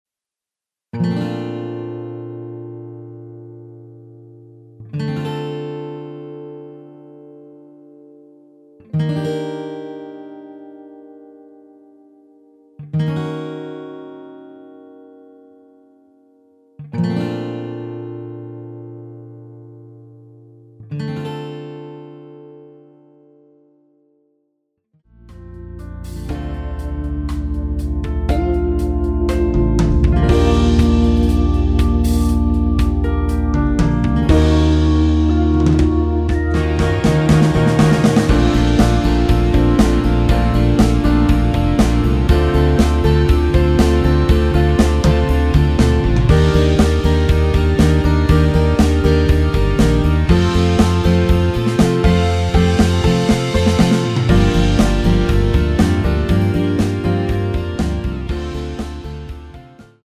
Bb
앞부분30초, 뒷부분30초씩 편집해서 올려 드리고 있습니다.
중간에 음이 끈어지고 다시 나오는 이유는